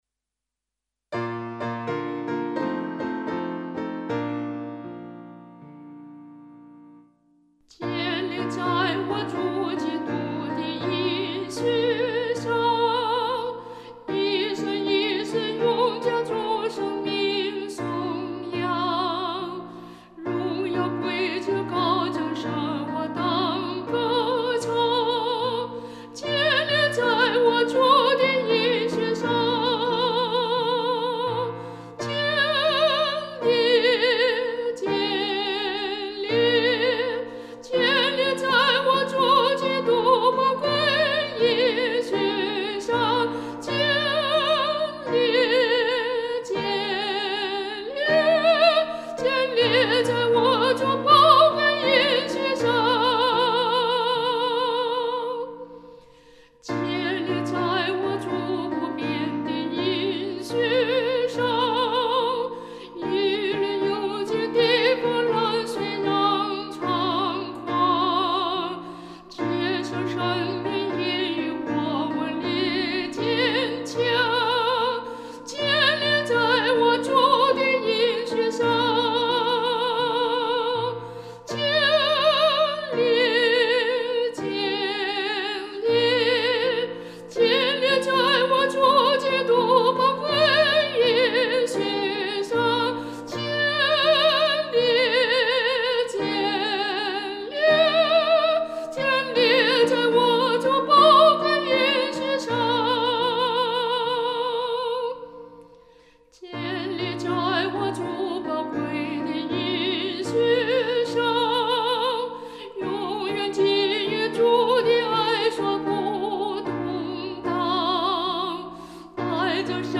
伴奏
受到他军旅生活的启发，这首赞美诗含有军事音乐的元素。